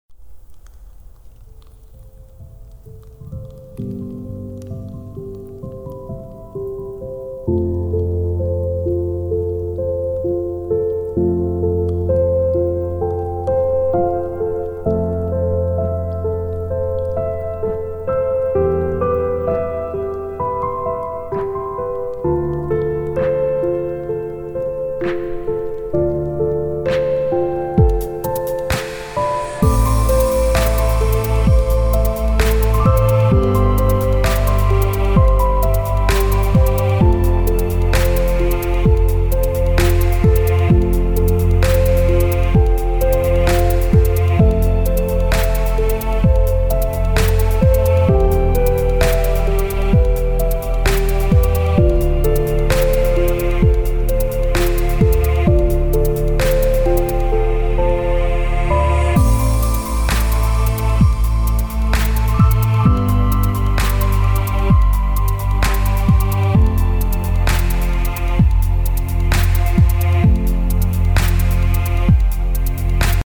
• Качество: 224, Stereo
красивые
спокойные
без слов
инструментальные